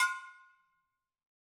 BrakeDrum1_Hammer_v2_Sum.wav